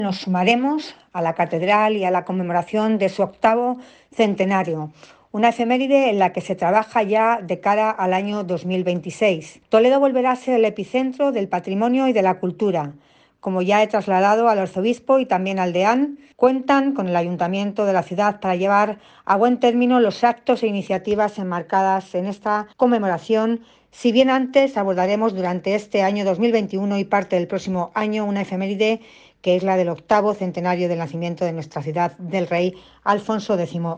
La alcaldesa de Toledo, Milagros Tolón, ha participa este 23 de enero, festividad de San Ildefonso y Día de la Ciudad, en la celebración litúrgica que ha presidido el arzobispo de Toledo, Francisco Cerro, en la Catedral Primada.
AUDIO. Milagros Tolón, alcaldesa de Toledo